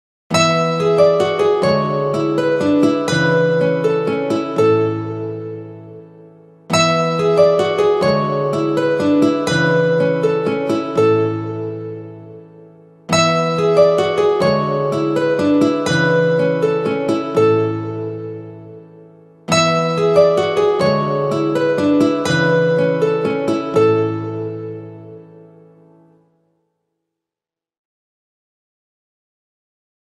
гитара